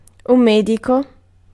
Ääntäminen
IPA: /ˈmɛ.di.kɔ/